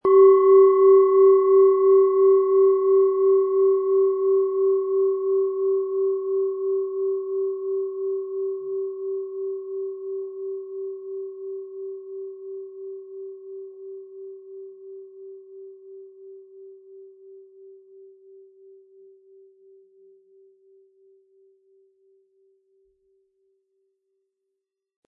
Tibetische Gelenk-Fuss- und Herz-Klangschale, Ø 12,2 cm, 260-320 Gramm, mit Klöppel
SchalenformBihar
MaterialBronze